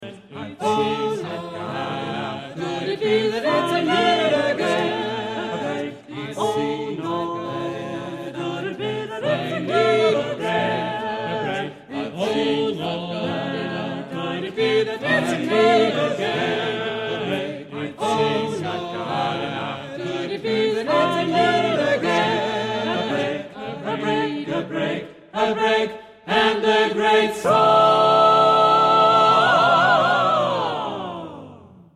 Género/Estilo/Forma: Canon ; Jazz ; Profano
Tipo de formación coral:  (3 voces iguales )
Tonalidad : la menor